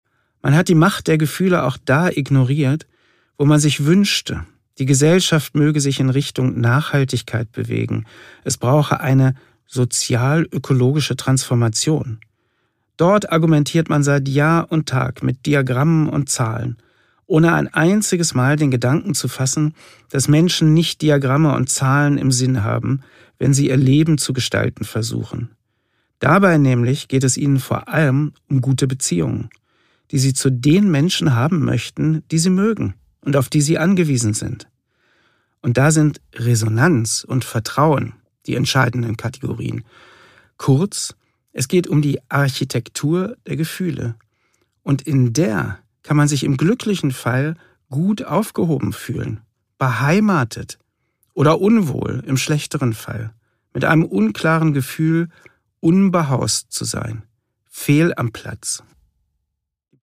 Produkttyp: Hörbuch-Download
Gelesen von: Prof. Dr. Harald Welzer